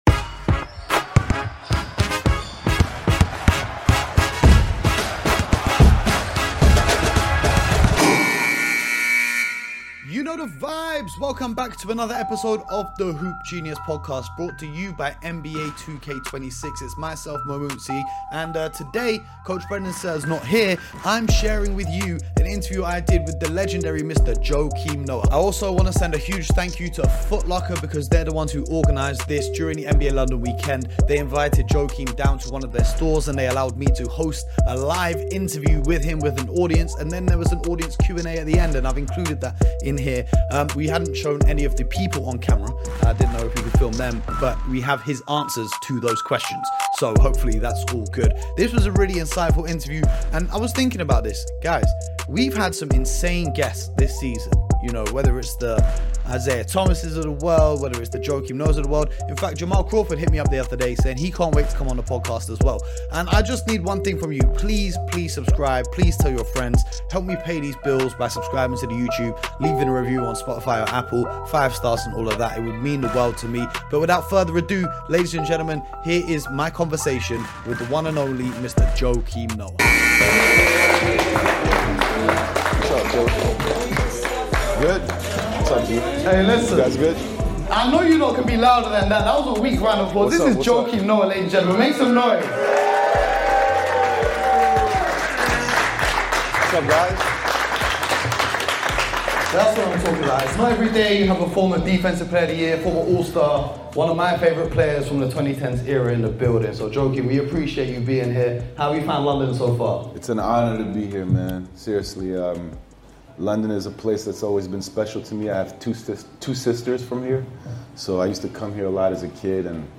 Joakim Noah interview: trash talk, beef with Obama & more